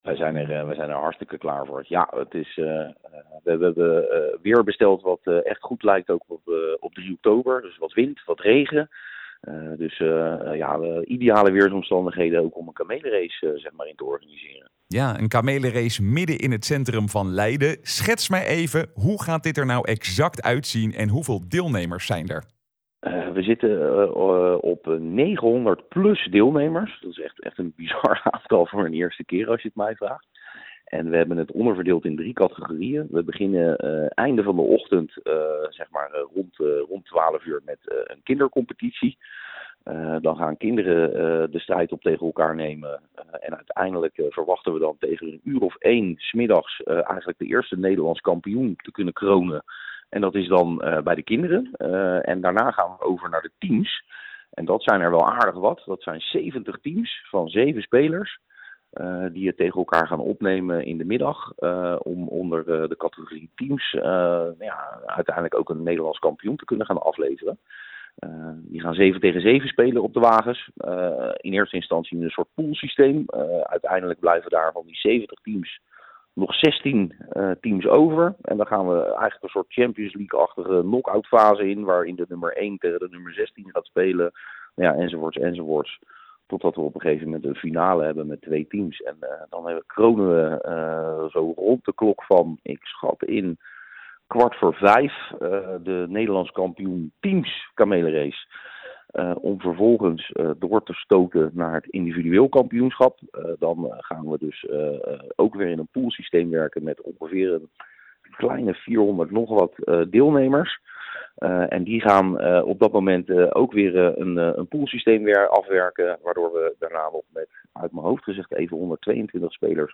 Presentator
in gesprek